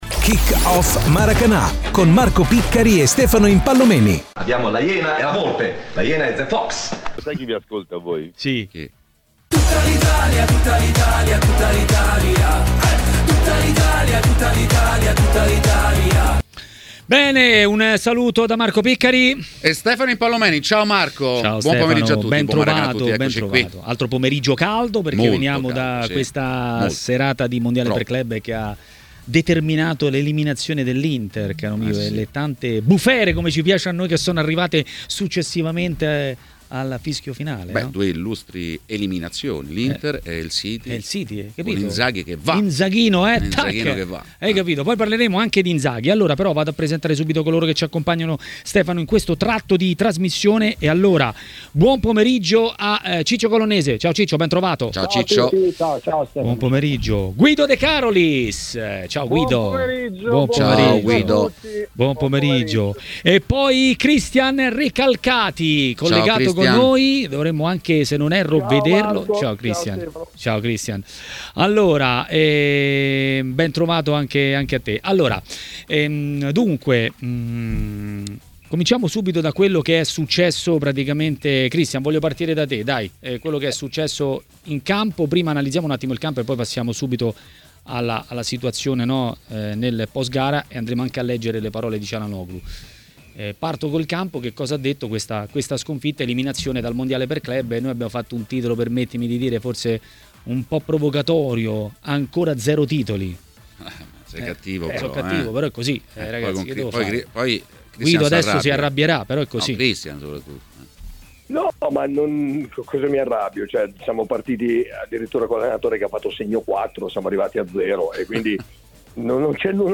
L'ex difensore Francesco Colonnese è stato ospite di TMW Radio, durante Maracanà.